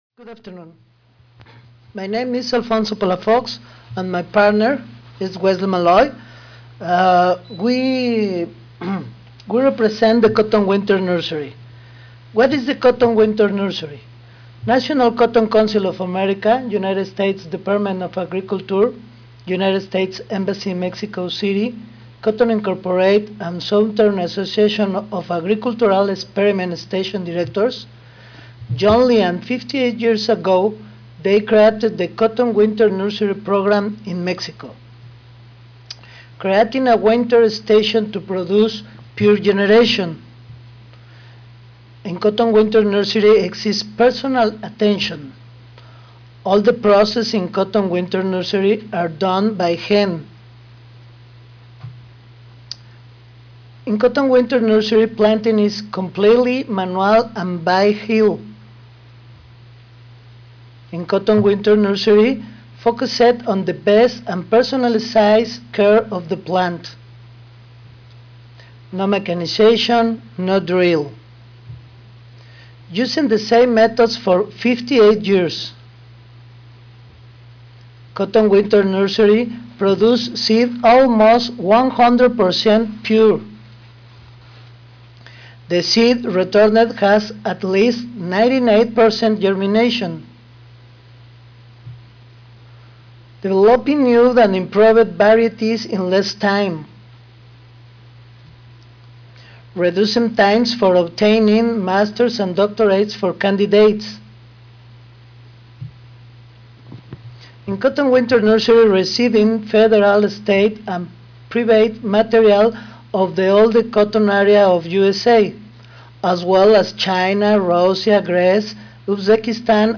Conf. Rooms 1-4 (Marriott Rivercenter Hotel)
Audio File Recorded presentation